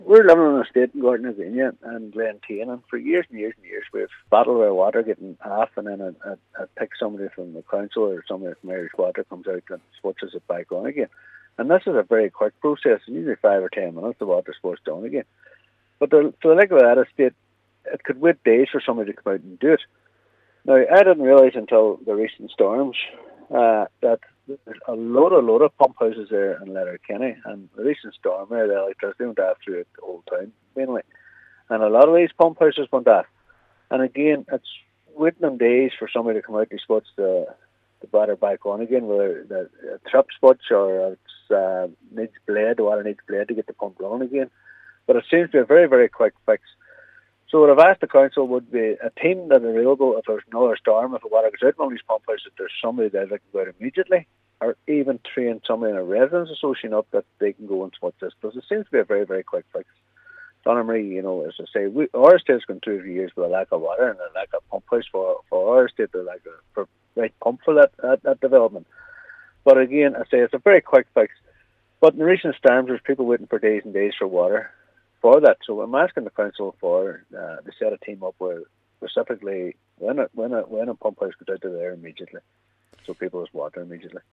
Councillor Devine suggests that local groups, such as residents’ associations, be trained to perform this task, benefiting all parties involved: